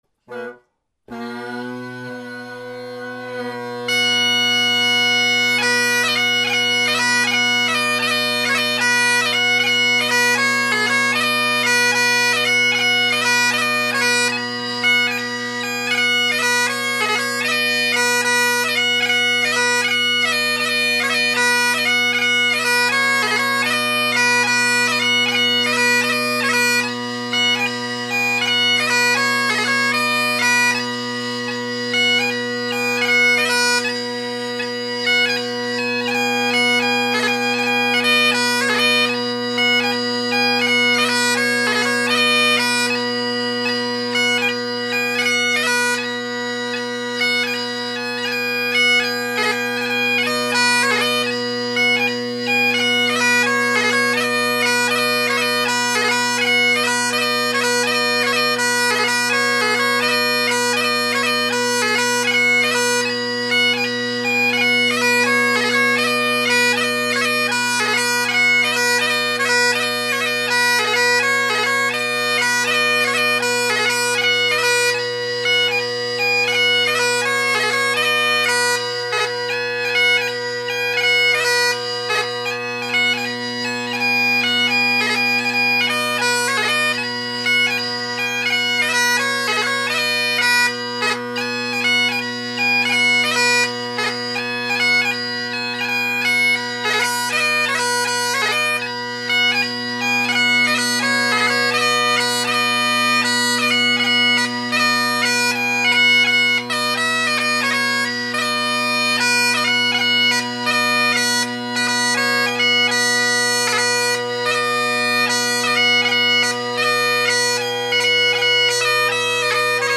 Great Highland Bagpipe Solo
Blowing in a Husk chanter reed for this pipe so steadiness is a bit of an issue as is tuning with this set of recordings.